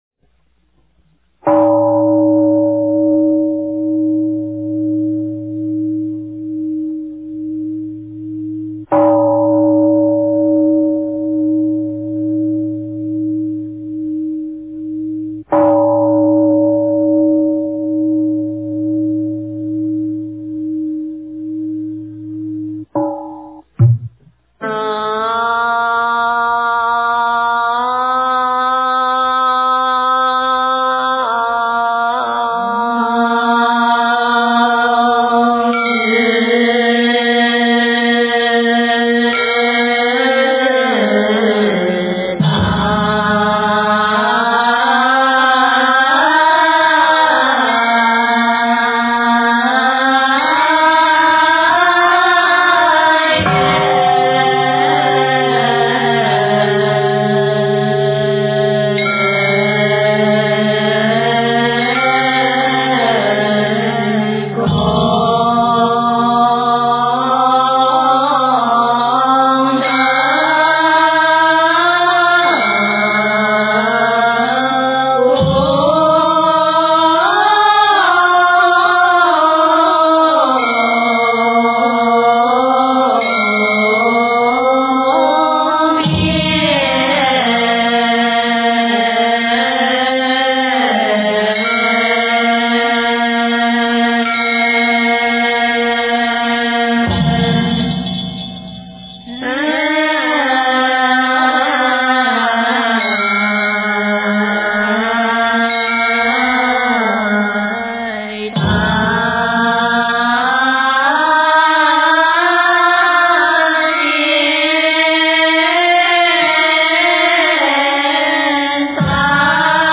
药师经-赞--圆光佛学院众法师
药师经-赞--圆光佛学院众法师 经忏 药师经-赞--圆光佛学院众法师 点我： 标签: 佛音 经忏 佛教音乐 返回列表 上一篇： 药师经--圆光佛学院众法师 下一篇： 初一十五法会--中国佛学院法师 相关文章 妙法莲华经观世音菩萨普门品--佛光山梵呗团 妙法莲华经观世音菩萨普门品--佛光山梵呗团...